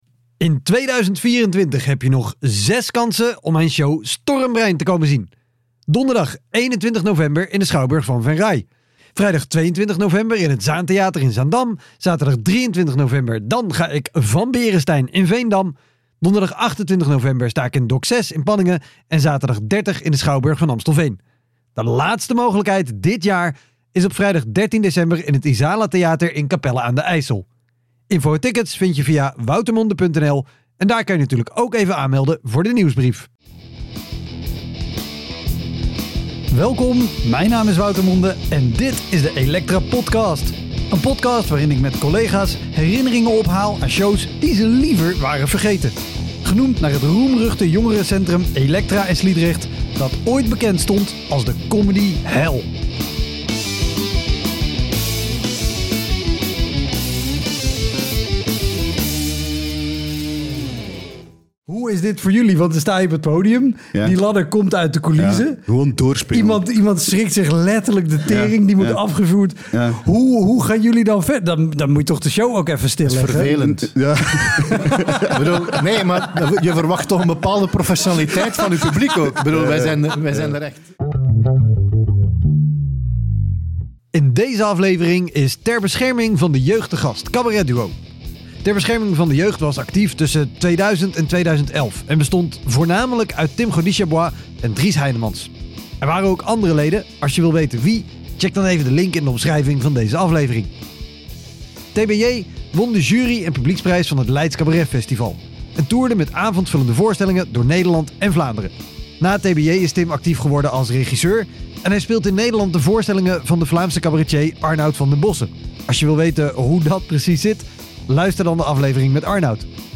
Comedians en cabaretiers vertellen de beste verhalen over hun slechtste shows.